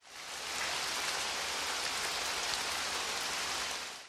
Мемы категории "Звуки природы"